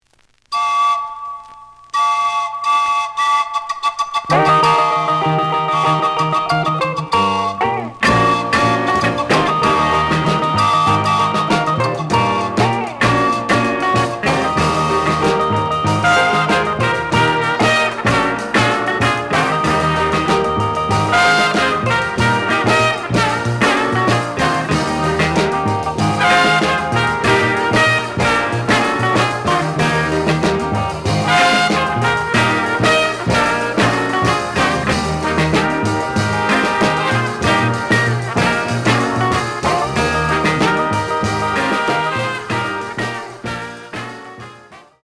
The audio sample is recorded from the actual item.
●Format: 7 inch
●Genre: Latin Jazz